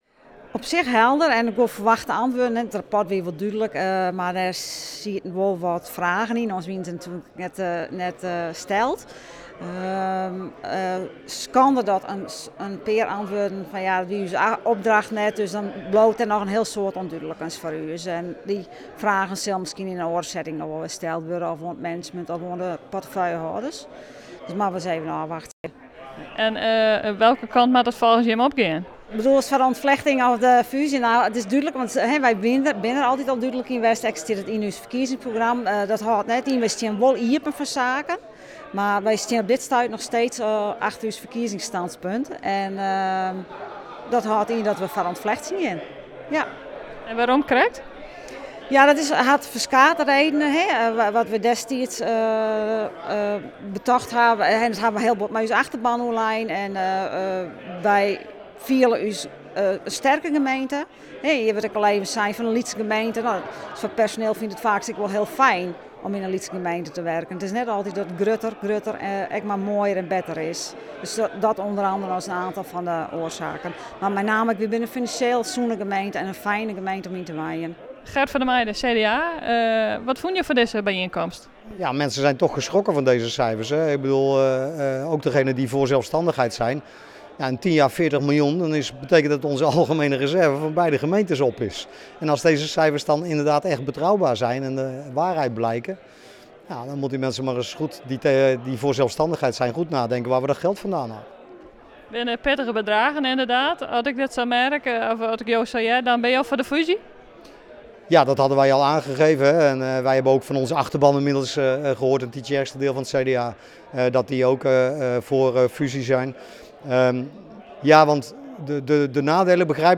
Raadslid Emmie Soepboer van de FNP in Tytsjerksteradiel en raadslid Gert van der Meijden van het CDA in Tytsjerksteradiel: